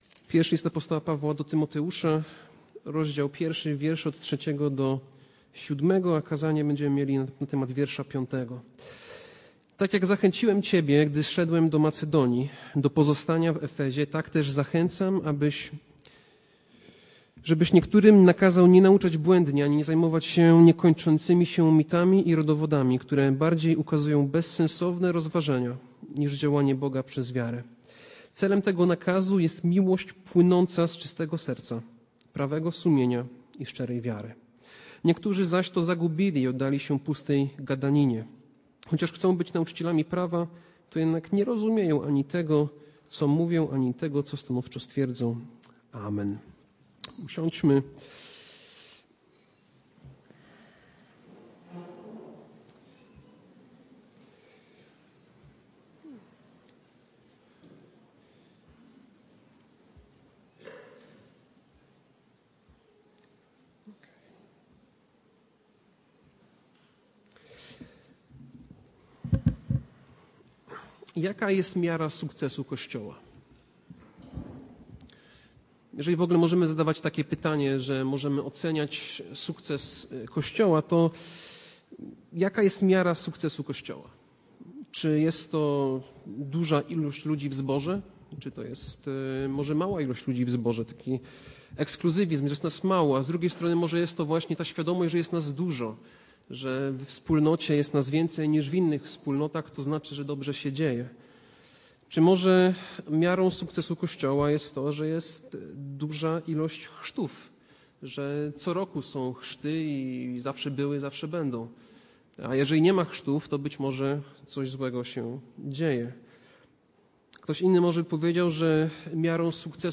Passage: I List do Tymoteusza 1, 3-7 Kazanie